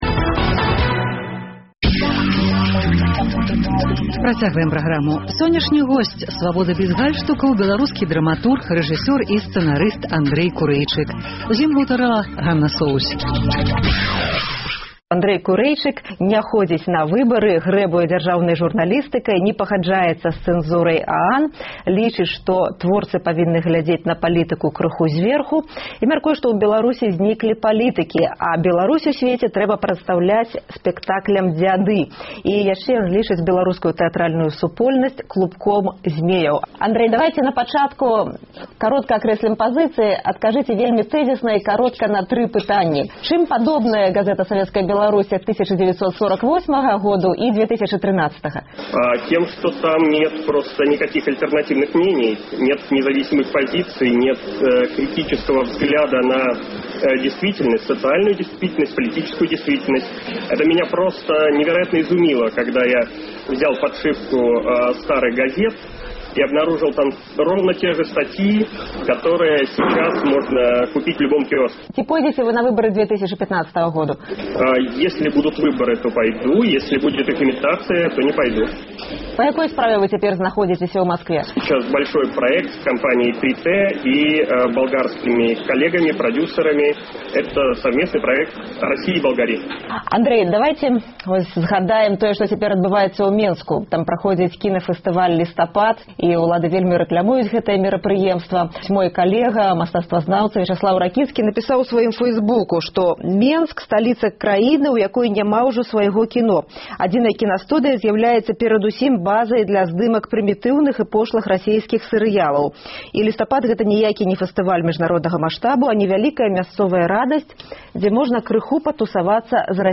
Госьць «Свабоды бяз гальштукаў» — драматург, рэжысэр і сцэнарыст Андрэй Курэйчык.